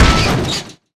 freezerHit2.wav